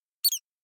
mouse_squeak.ogg